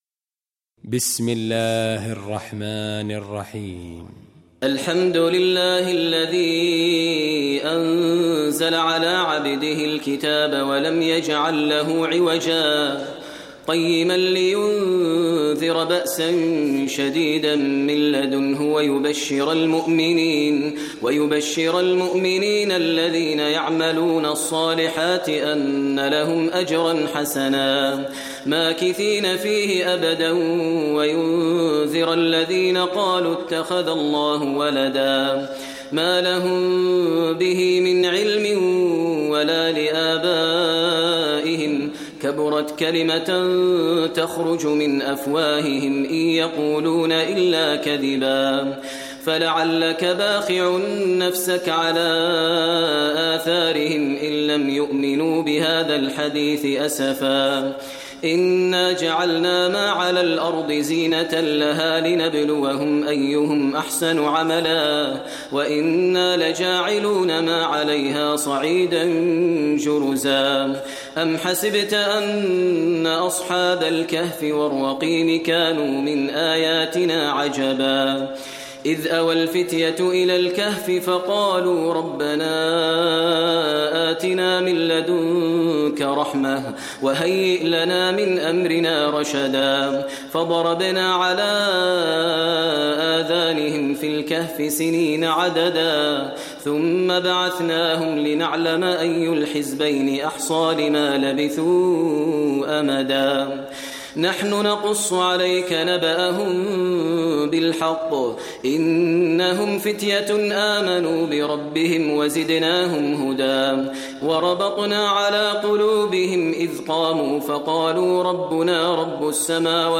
Surah al Kahf, listen online mp3 tilawat / recitation in Arabic recited by Imam e Kaaba Sheikh Maher al Mueaqly.